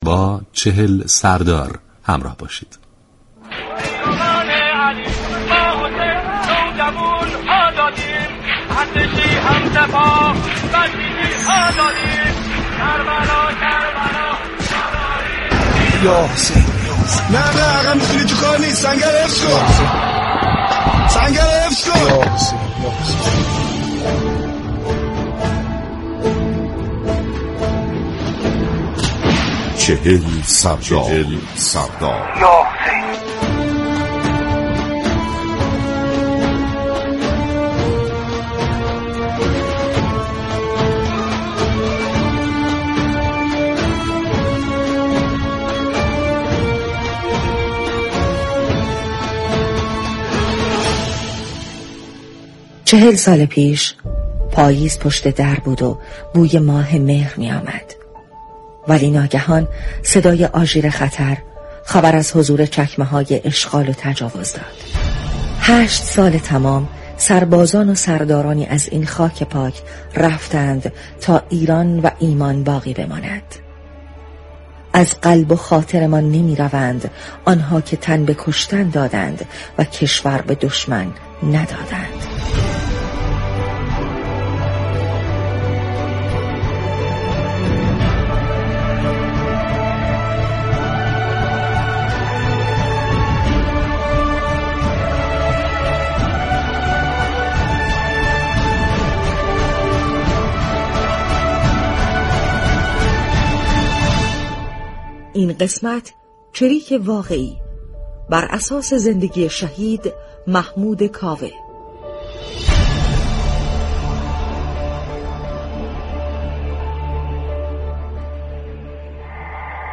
برنامه "چهل سردار" ویژه برنامه ای به مناسبت هفته دفاع مقدس است كه هر شب ساعت 20:30 به مدت 25 دقیقه از شبكه رادیویی ورزش پخش می شود.